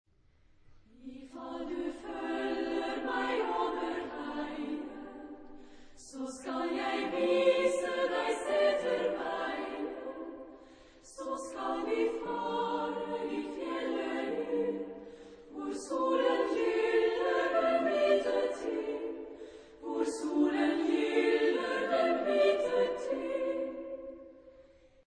Genre-Stil-Form: Volkstümlich ; Lied
Charakter des Stückes: allegretto
Chorgattung: SSA  (3 Frauenchor Stimmen )
Tonart(en): C-Dur